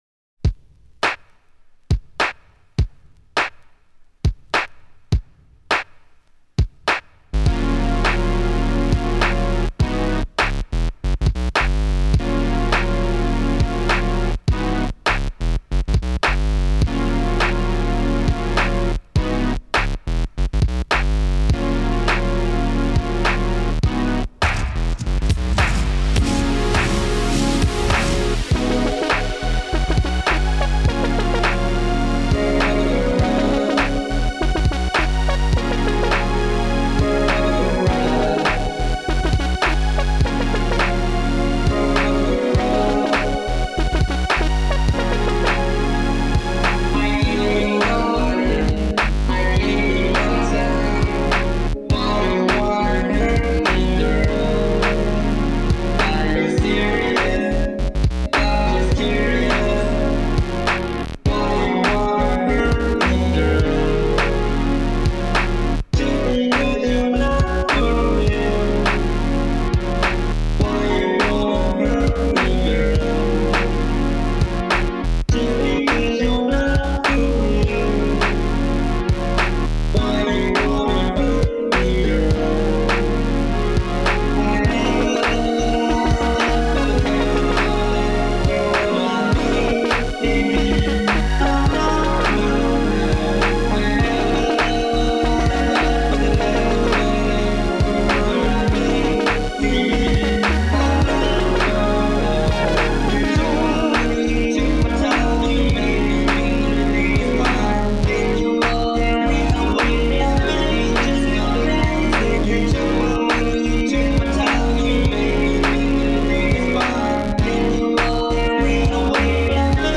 Vocal Remix
Instrumental Remix